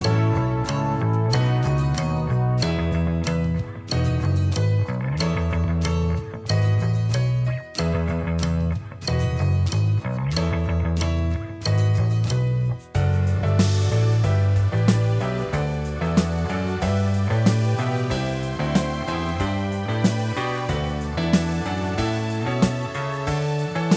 Minus Main Guitar Pop (1970s) 4:19 Buy £1.50